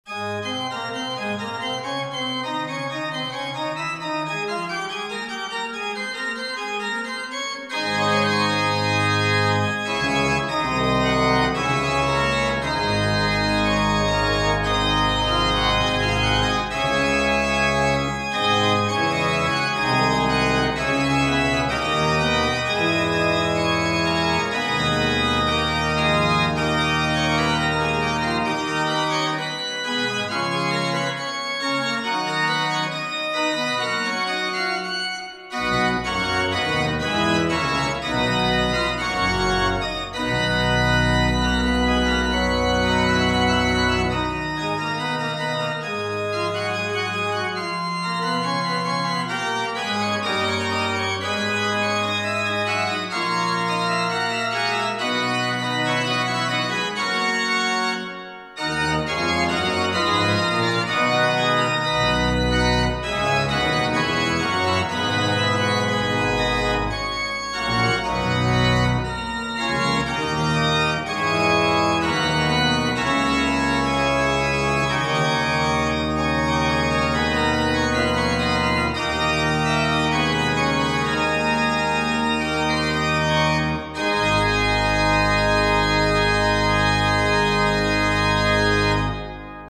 Church Organ Reverb This example illustrates how The FIReverb Suite™ has been used to add reverberation to a close recorded church organ:
A direct mono mix of four channels recorded at close positions right up at the organ at +/-3.5 and +/-2 m from the center. You hear some of the real church reverb but it is quite weak.
Mono mix
organ_direct_monomix.mp3